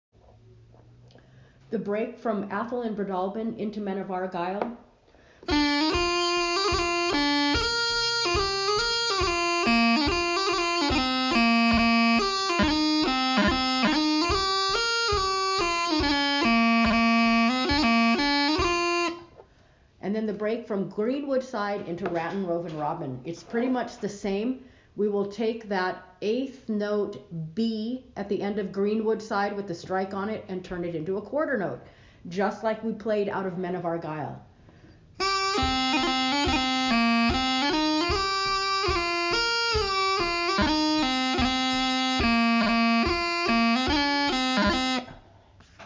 Pipes are currently tuning at 479-480 kHz.